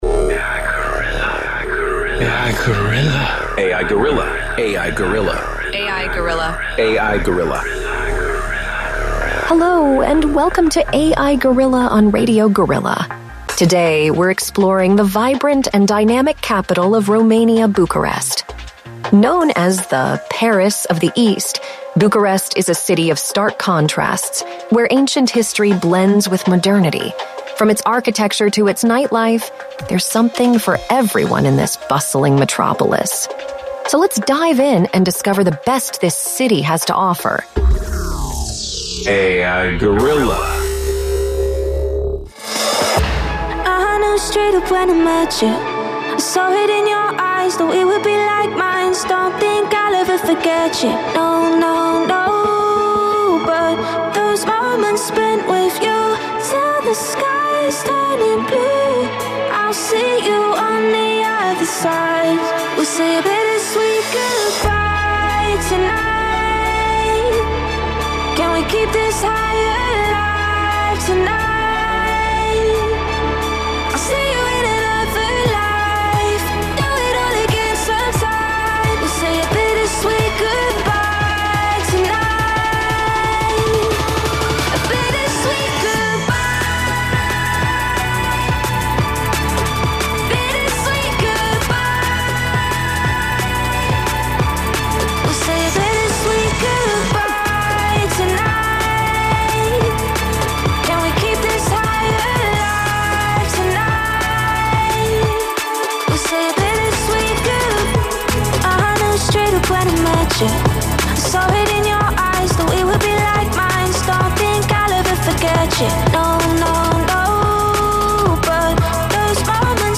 Pe 6 martie 2023, de la ora 10 dimineața, am difuzat “A.I. Guerrilla”, prima emisiune radio realizată de Inteligența Artificială în FM-ul românesc din câte știm noi, dacă nu chiar în Galaxie pe România, un experiment care va dura o oră.